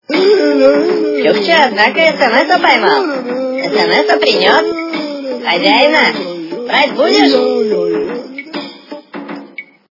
» Звуки » для SMS » Голос - Чукча однако SMSа поймал Хозяина брать будешь
При прослушивании Голос - Чукча однако SMSа поймал Хозяина брать будешь качество понижено и присутствуют гудки.